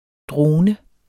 drone substantiv, fælleskøn Bøjning -n, -r, -rne Udtale [ ˈdʁoːnə ] Oprindelse fra middelnedertysk drone , af en rod med betydningen 'brumme' Betydninger 1.